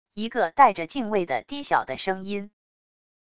一个带着敬畏的低小的声音wav音频